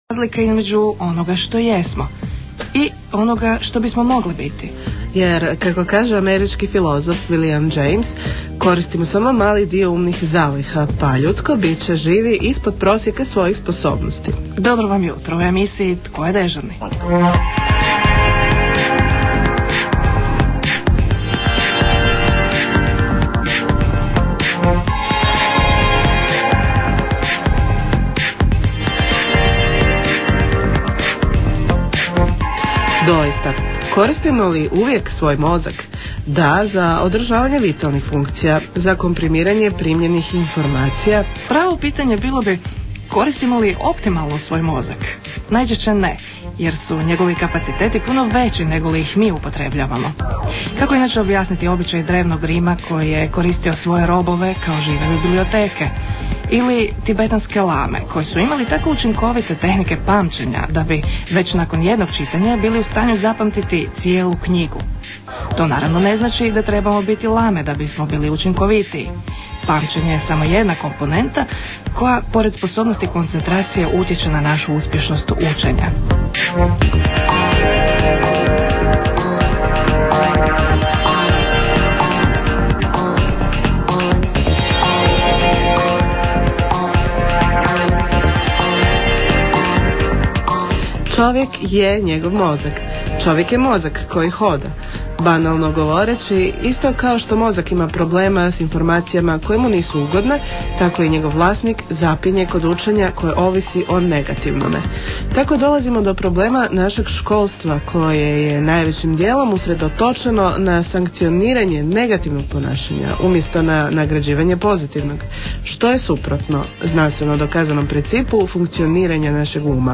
>Poslu�ajte razgovor o u�enju!